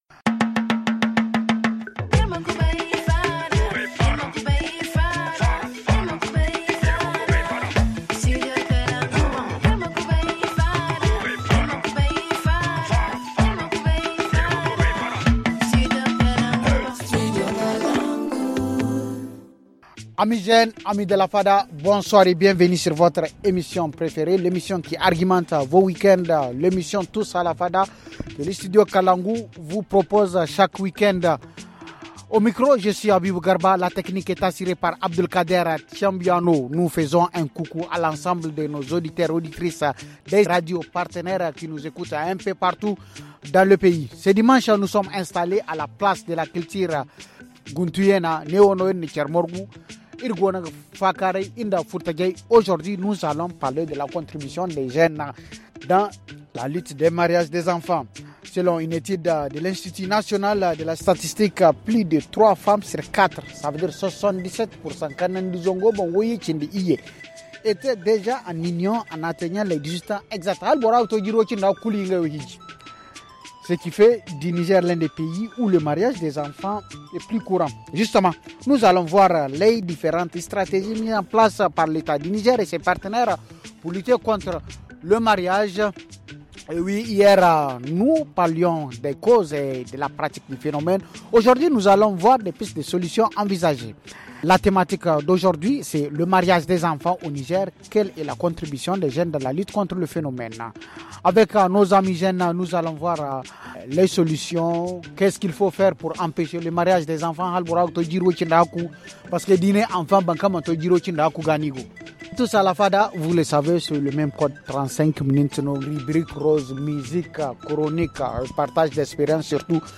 Ce dimanche, nous sommes installés à la place de la culture sur le site de l’ancien marché de Djamadjé pour parler de la contribution des jeunes dans la lutte contre le mariage des enfants.